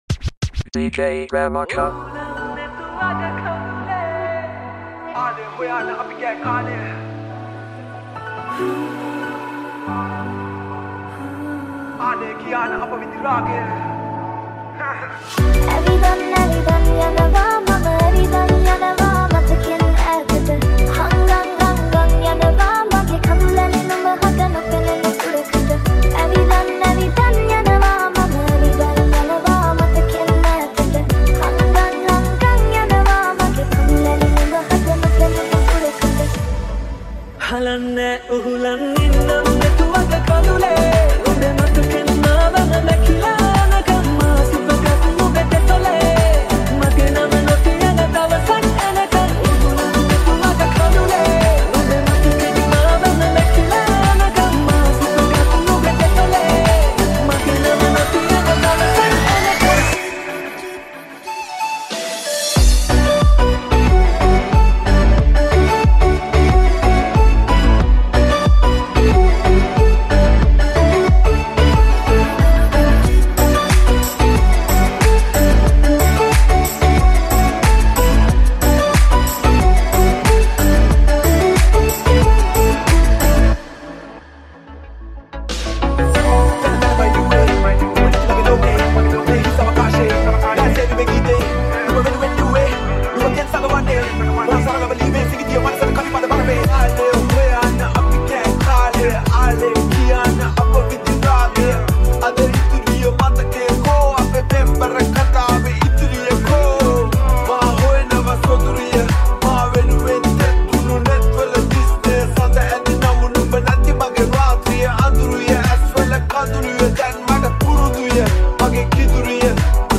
Tropical House Remix